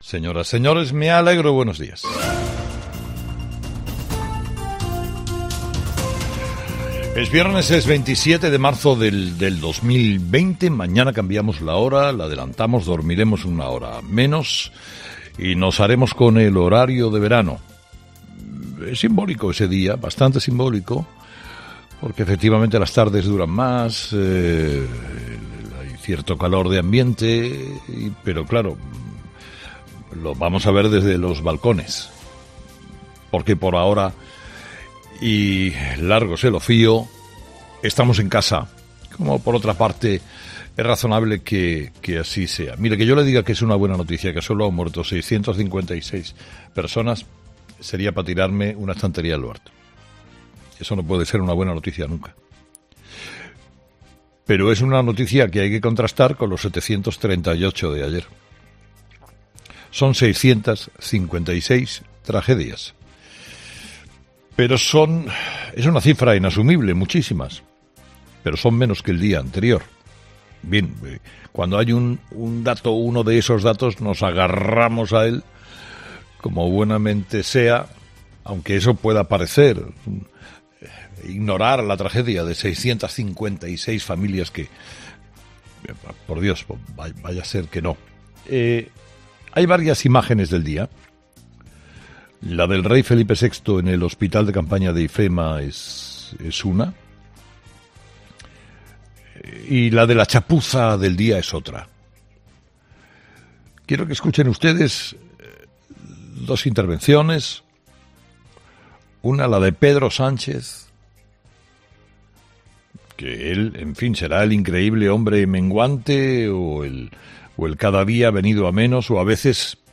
Esta es la denuncia que ha hecho el periodista en 'Herrera en COPE'